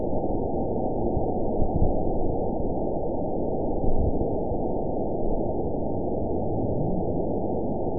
event 919916 date 01/28/24 time 10:00:51 GMT (1 year, 3 months ago) score 8.90 location TSS-AB03 detected by nrw target species NRW annotations +NRW Spectrogram: Frequency (kHz) vs. Time (s) audio not available .wav